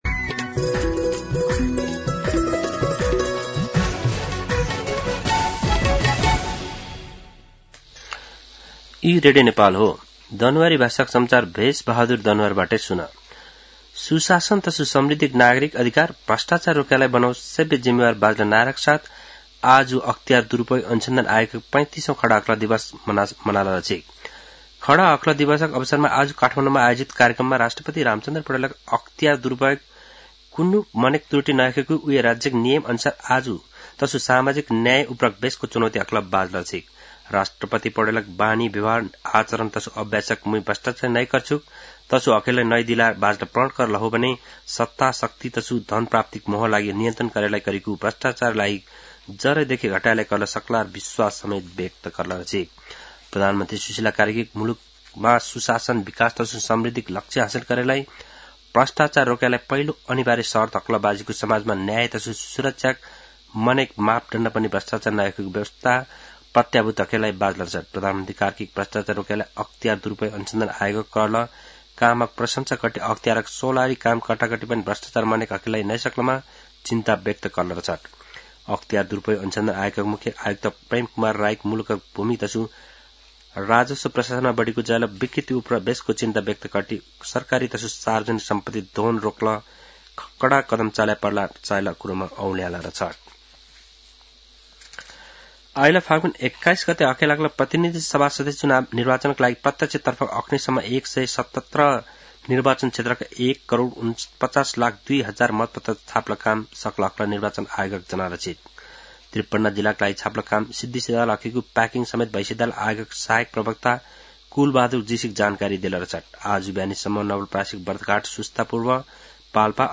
दनुवार भाषामा समाचार : २८ माघ , २०८२
Danuwar-News-10-28.mp3